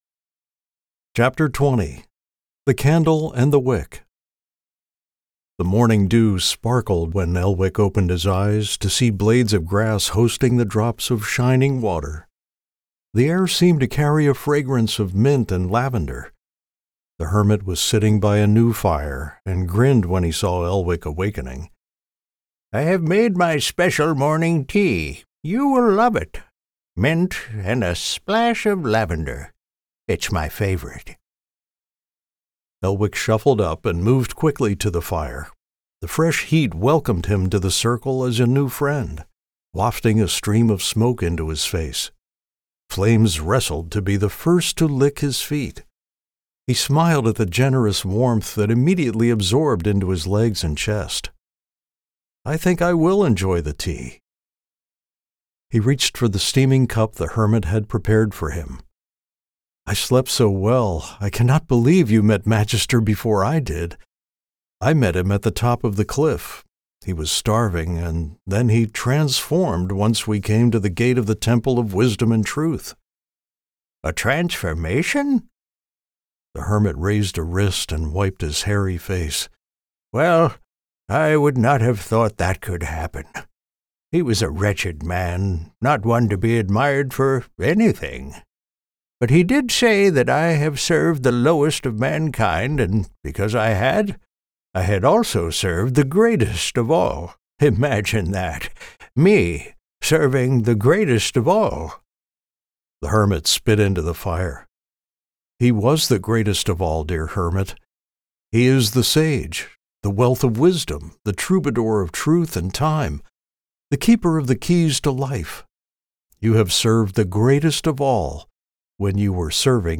Audiobook Production